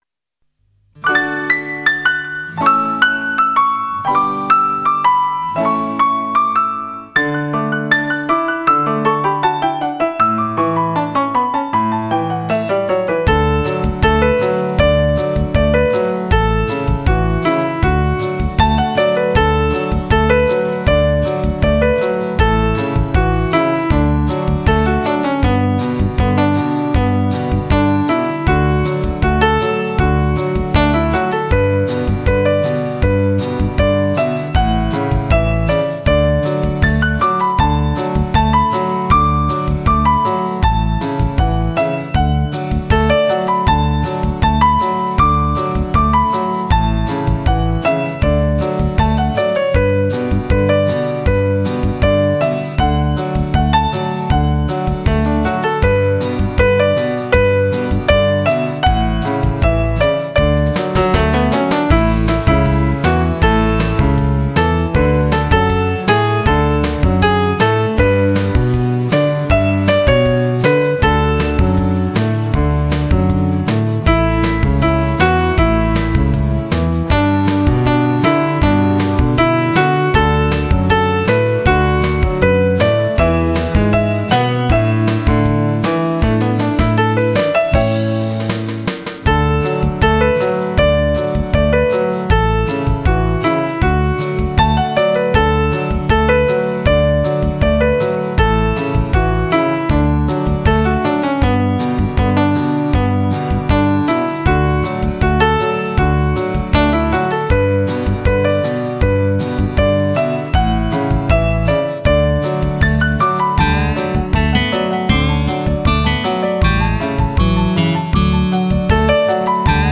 93年开始谱写一些放松，治疗，冥想专辑，赢得好多国际大奖。